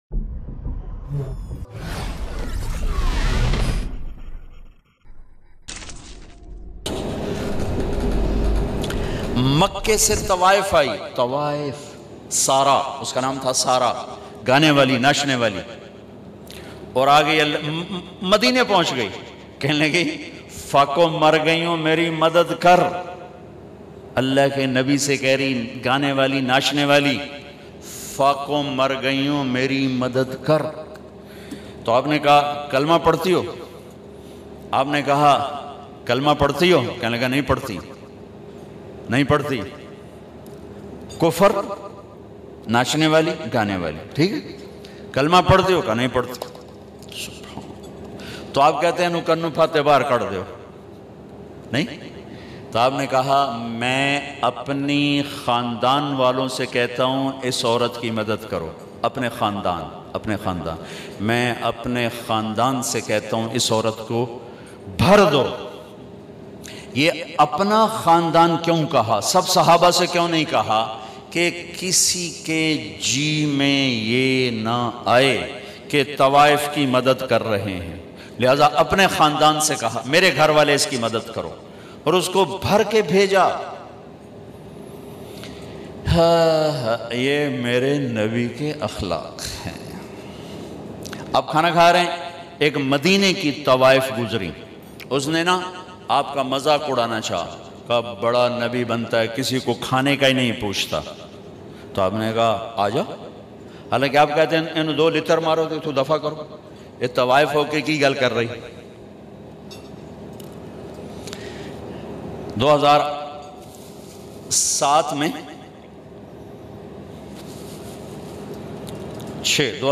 Kaaba Ki Aik Tawaif Maulana Tariq Jameel Bayan mp3
Maulana Tariq Jameel Bayan Kaaba Ki Aik Tawaif.mp3